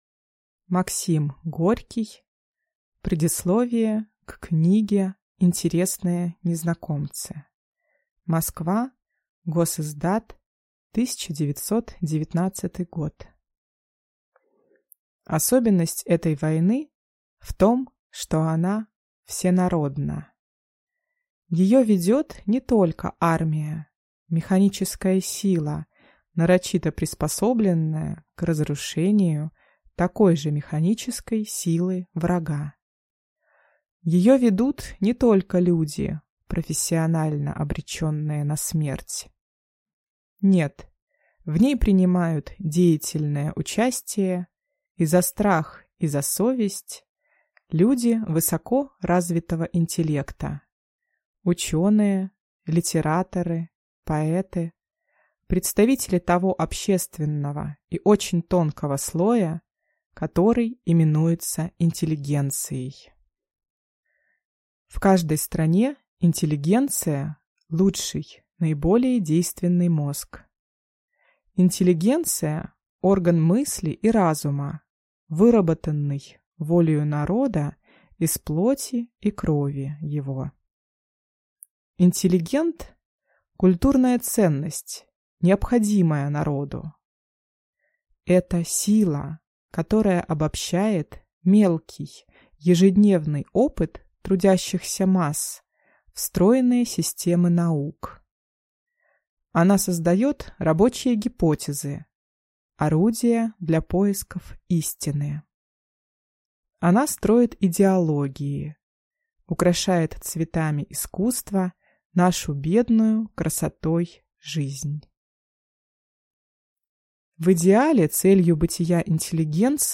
Аудиокнига Предисловие к книге «Интересные незнакомцы» | Библиотека аудиокниг